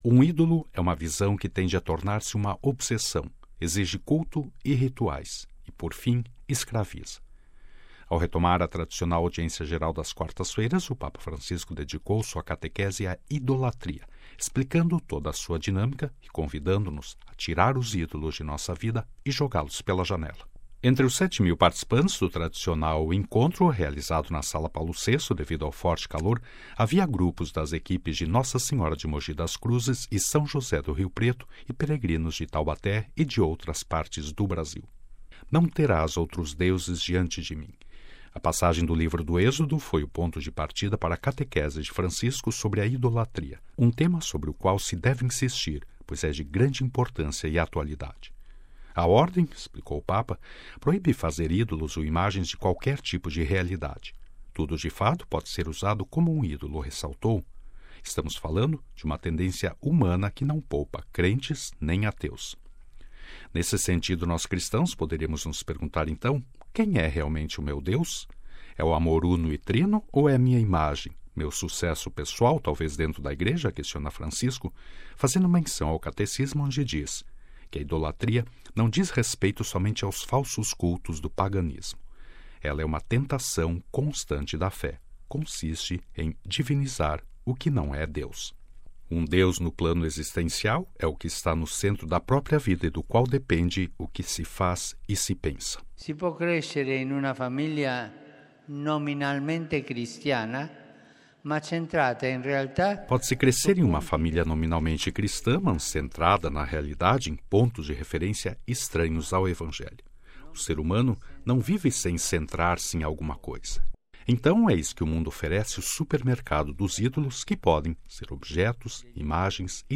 Entre os 7 mil participantes do tradicional encontro, realizado na Sala Paulo VI devido ao forte calor, havia grupos das Equipes de Nossa Senhora de Mogi das Cruzes e São José do Rio Preto, e peregrinos de Taubaté e de outras localidades do Brasil.